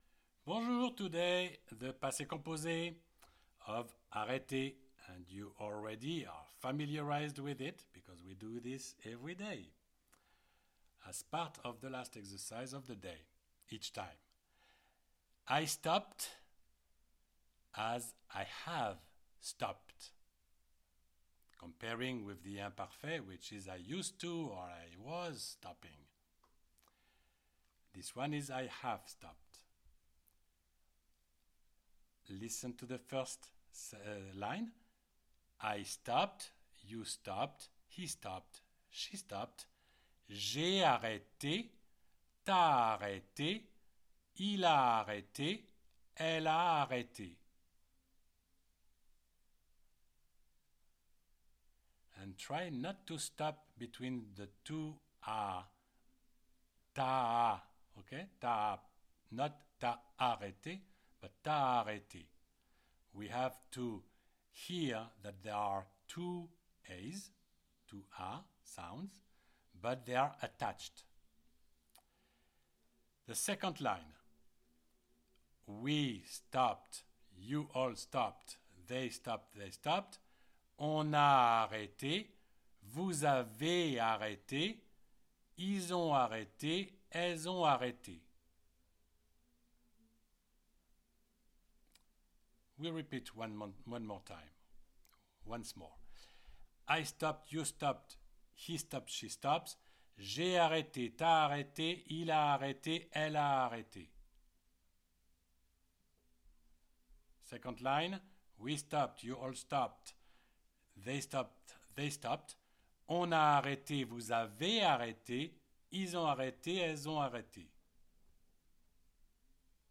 CLICK ON THE PLAY BUTTON BELOW TO PRACTICE ‘ARRÊTER’, ‘TO STOP’, IN PASSÉ COMPOSÉ Just the sight of the classic French Conjugation Chart makes it difficult to be Advanced French .
You read and you repeat with the audio.